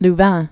(l-văɴ) also Leu·ven (lœvən)